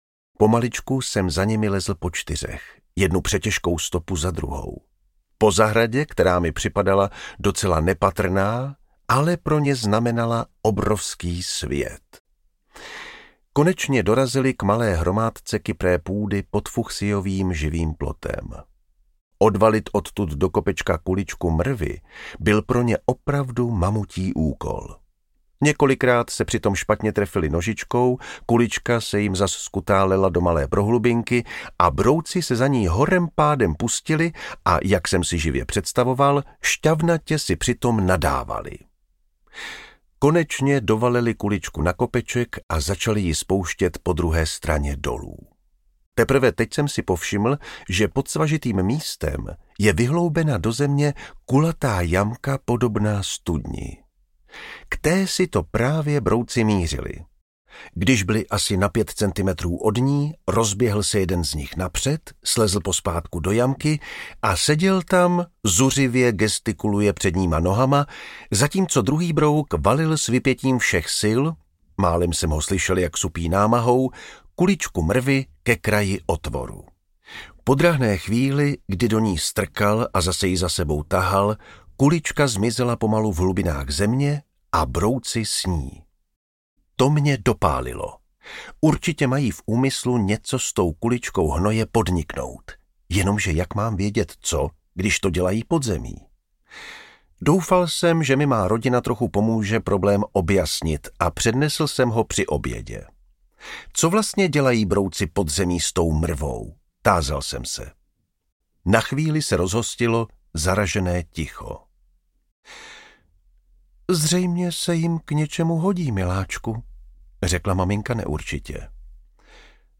Ptáci, zvířata a moji příbuzní audiokniha
Ukázka z knihy
• InterpretJan Vondráček